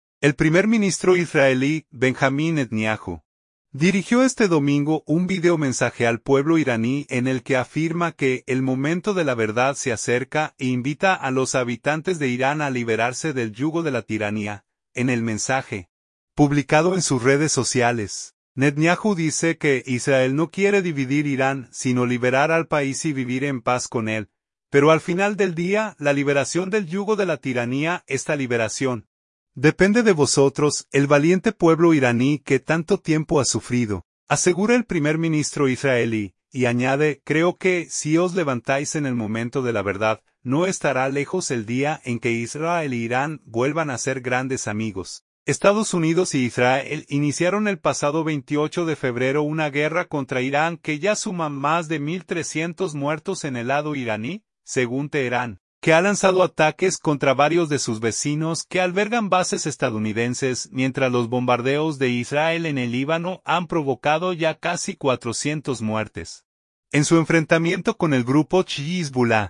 El primer ministro israelí, Benjamín Netanyahu, dirigió este domingo un vídeomensaje al pueblo iraní en el que afirma que “el momento de la verdad se acerca” e invita a los habitantes de Irán a liberarse “del yugo de la tiranía”.